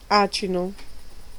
Ääntäminen
Vaihtoehtoiset kirjoitusmuodot baye Synonyymit anse mystification poisson d'avril bourde crique calanque cassade petit fruit rade Ääntäminen France (Paris): IPA: [yn bɛ] Tuntematon aksentti: IPA: /bɛ/ IPA: /be/